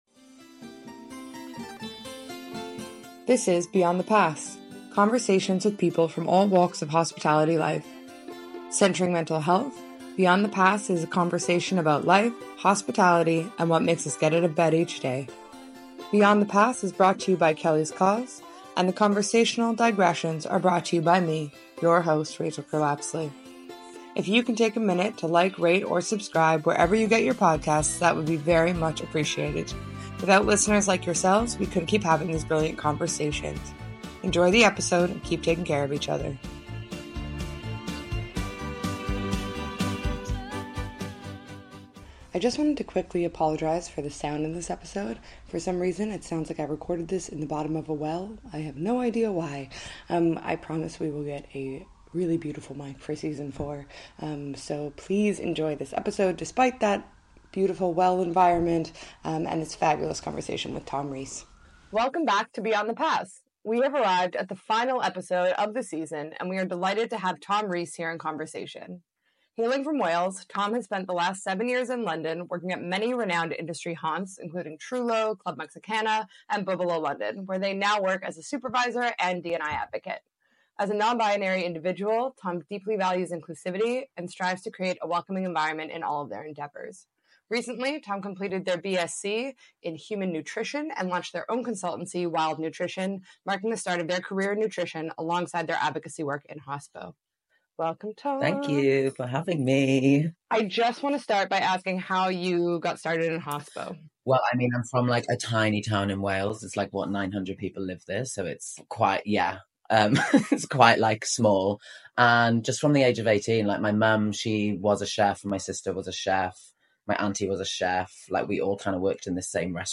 It's the final episode of the season! For some reason it sounds like I recorded this in the bottom of a well!